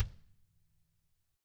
DW HARD PD-R.wav